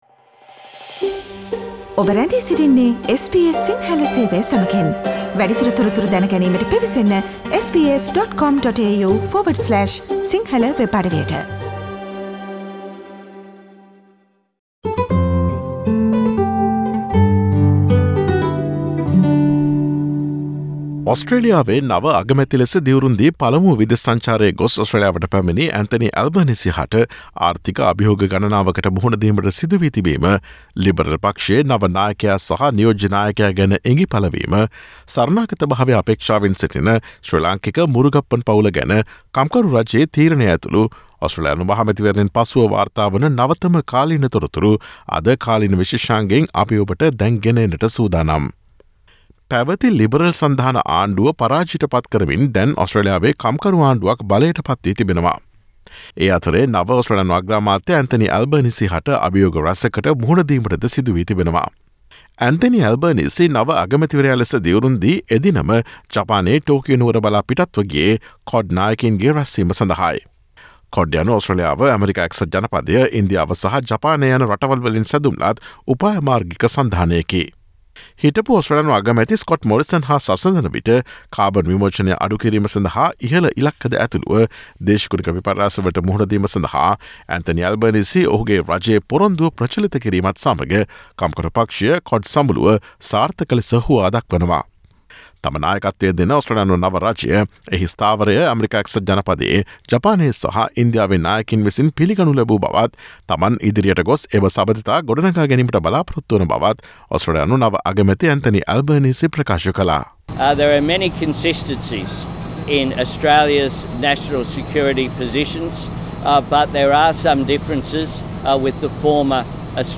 ඔස්ට්‍රේලියානු නව රජයට බරපතල ආර්ථික අභියෝග ගණනාවකට මුහුණ දීමට සිදුවී තිබීම ඇතුළු ඔස්ට්‍රේලියානු දේශපාලනයේ වාර්තා වන නවතම තොරතුරු රැගත් මැයි 26 වන දා බ්‍රහස්පතින්දා ප්‍රචාරය වූ SBS සිංහල සේවයේ කාලීන තොරතුරු විශේෂාංගයට සවන්දෙන්න.